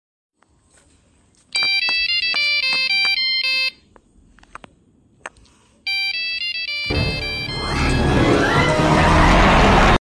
you know That classic ringtone.